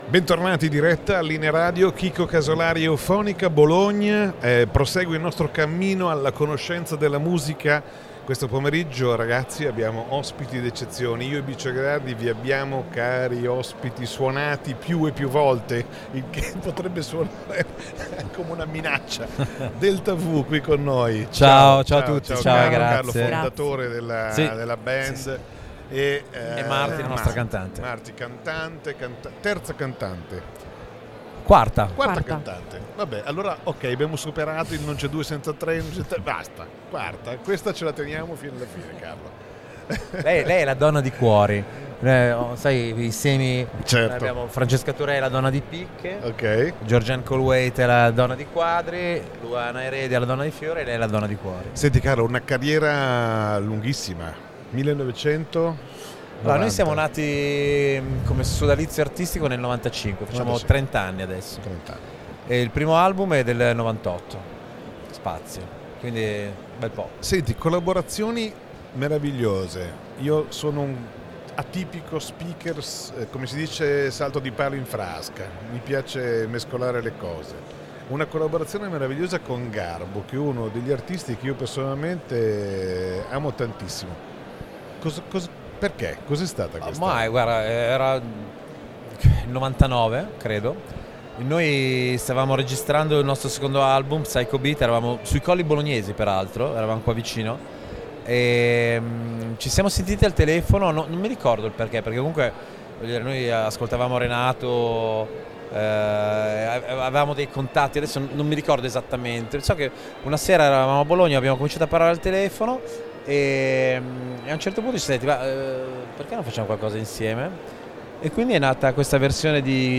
Intervista al gruppo Delta V